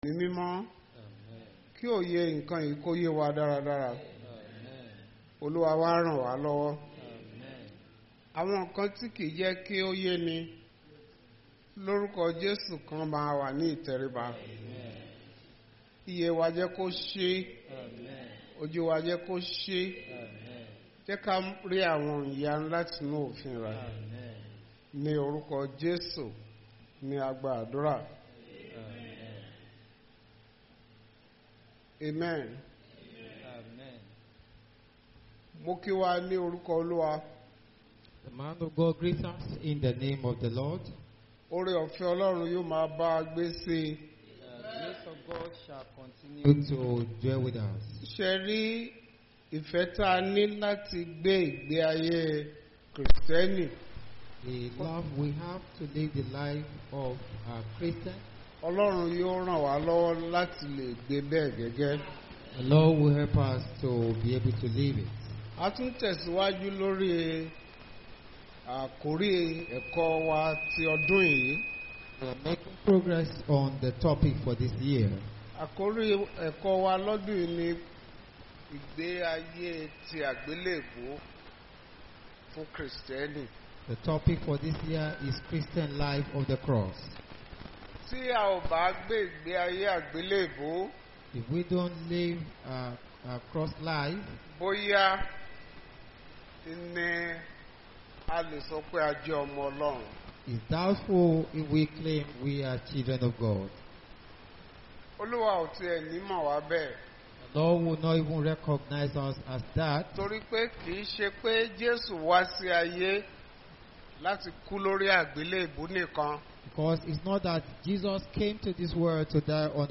Bible Class Passage: Matthew 10:33, Mark 8:38, Hebrews 13:12-13, 1 John 2:28